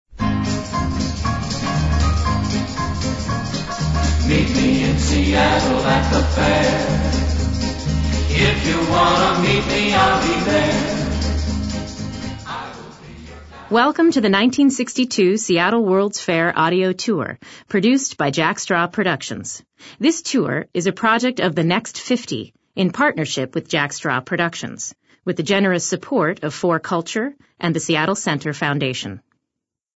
A self-guided audio tour produced by Jack Straw and The Next Fifty